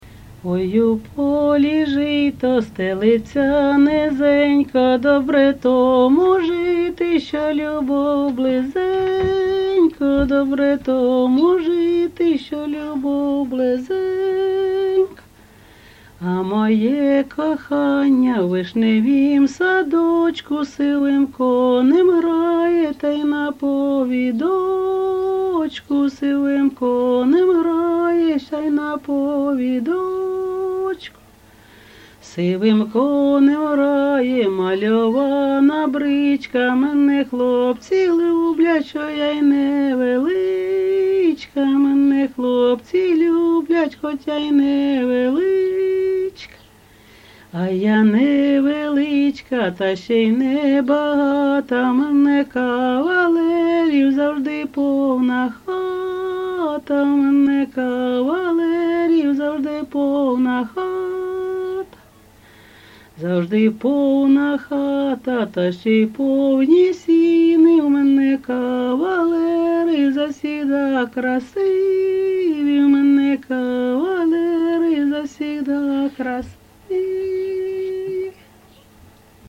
ЖанрПісні з особистого та родинного життя
Місце записус. Серебрянка, Артемівський (Бахмутський) район, Донецька обл., Україна, Слобожанщина